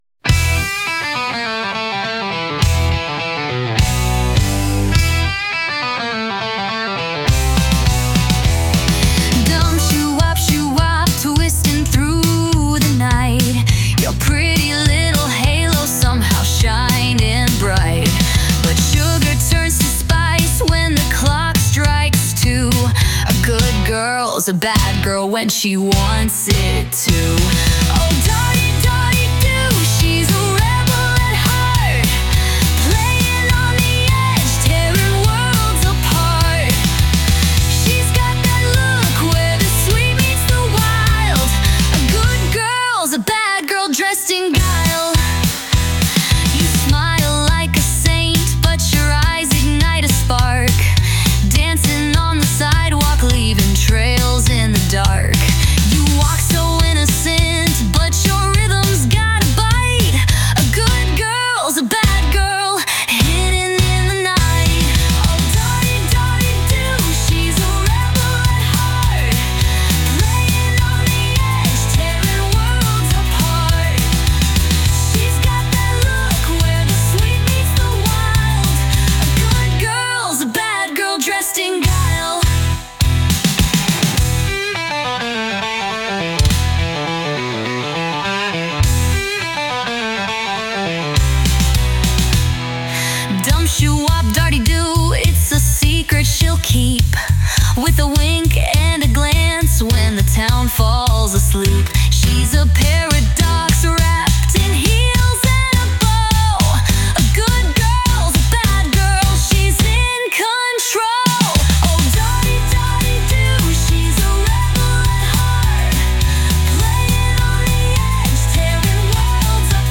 Arabian-Ornamental